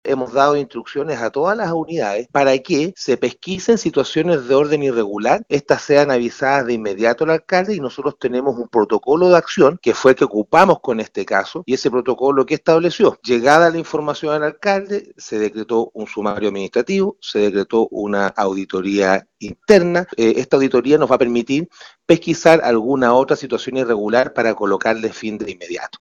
Antecedentes graves de los que se refirió el alcalde de Loncoche, Alexis Pineda, quien confirmó que se decretó un sumario administrativo y a la vez una auditoría interna en el DAEM.